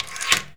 door_lock_slide_01.wav